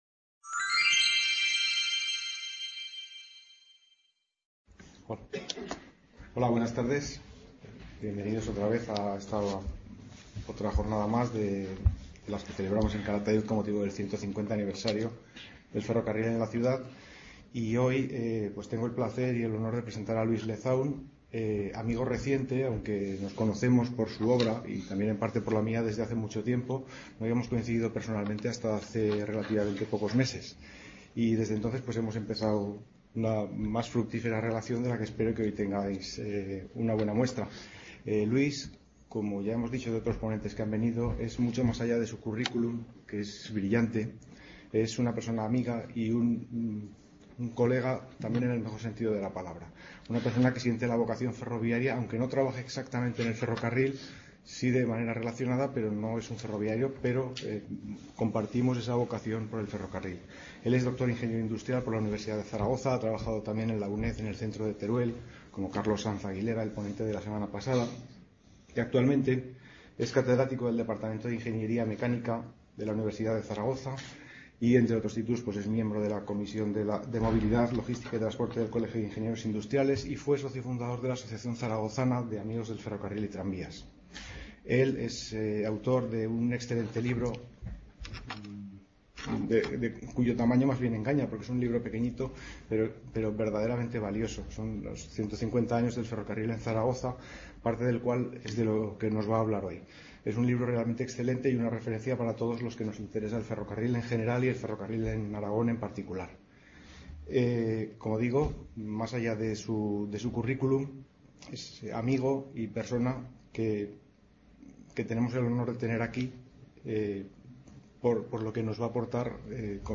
Description Ciclo de seis conferencias sobre el ferrocarril, impartidas por expertos en la materia y abiertas a todo el público. Con motivo del 150 Aniversario de la llegada del ferrocarril a la Estación de Calatayud.